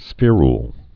(sfîrl, -yl, sfĕr-)